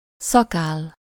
Ääntäminen
Ääntäminen France: IPA: [baʁb] Haettu sana löytyi näillä lähdekielillä: ranska Käännös Ääninäyte Substantiivit 1. szakáll Suku: f .